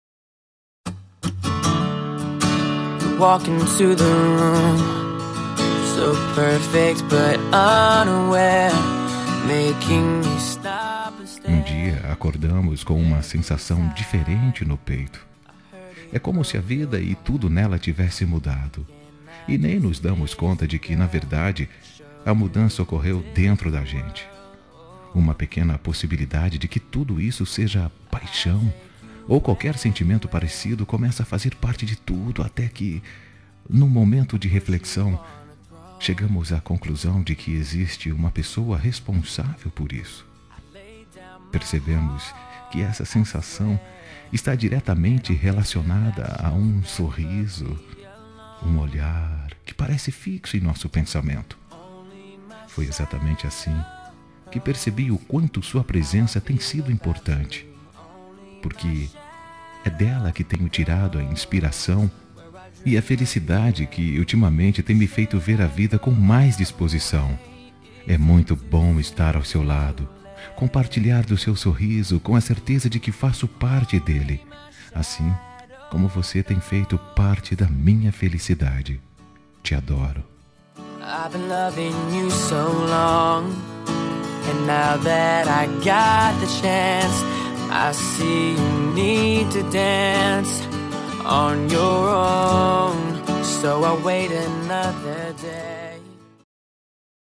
Telemensagem Início de Namoro – Voz Masculina – Cód: 755